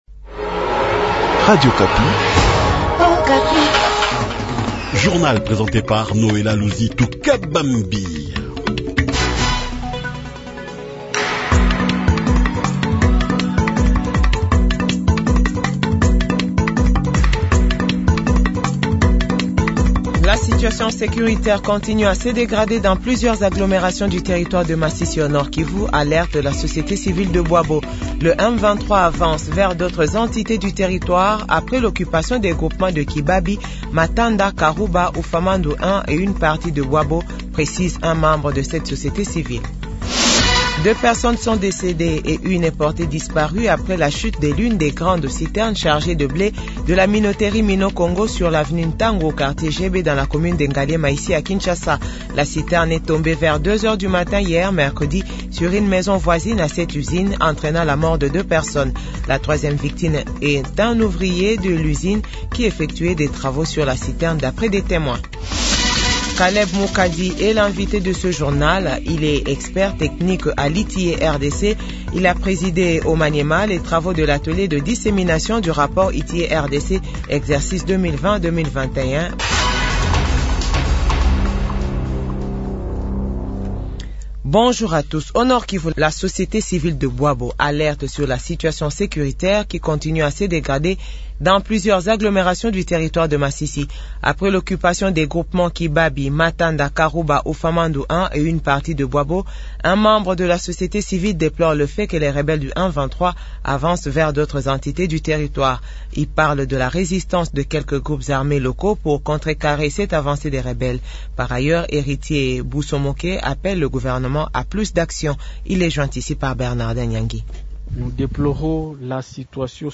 JOURNAL FRANCAIS 8H00